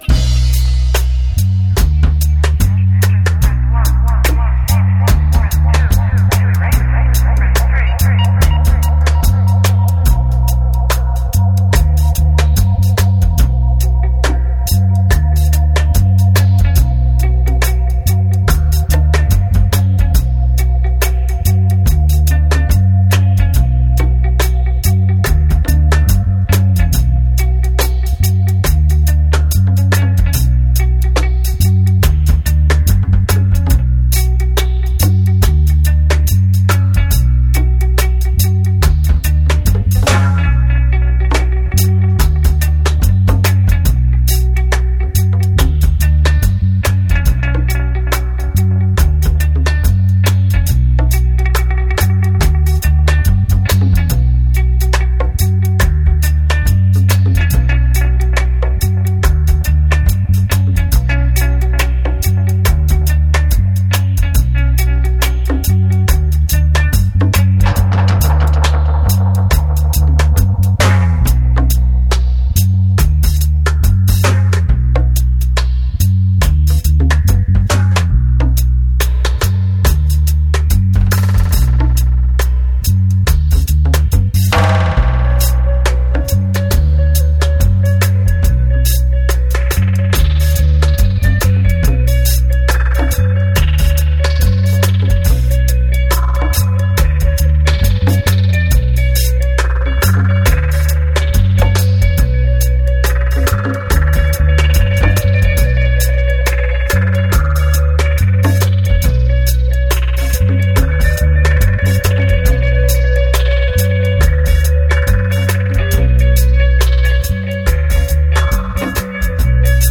Genre: Dub.